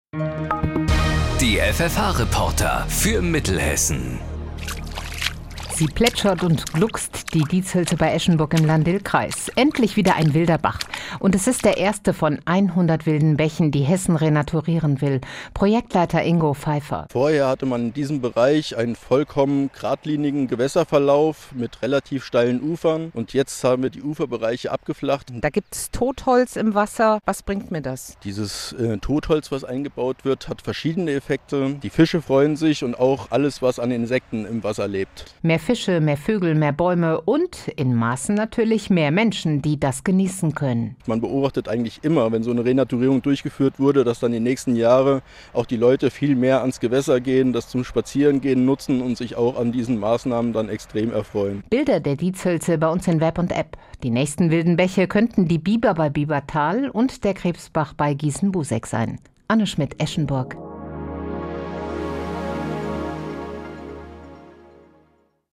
Bericht von Hit Radio FFH vom 18.06.2021